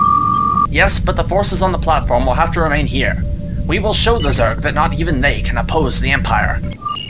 Human Male, Age 48